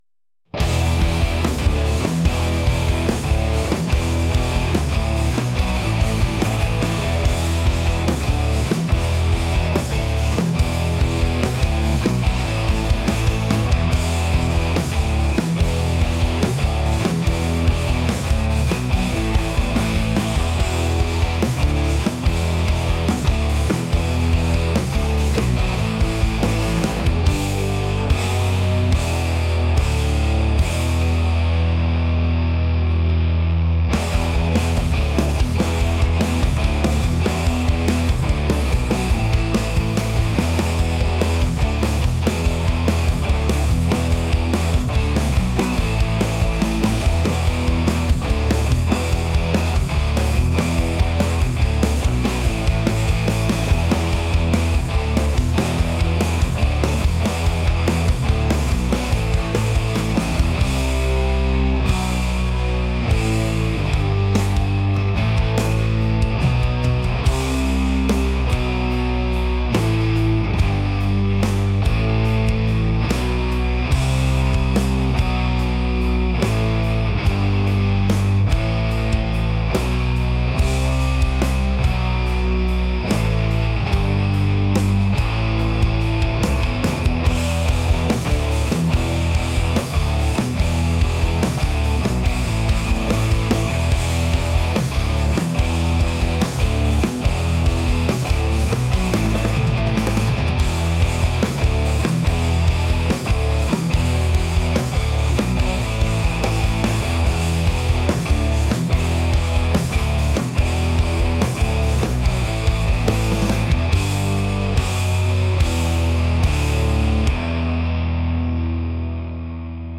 alternative | rock | intense